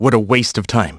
Riheet-Vox_Skill3.wav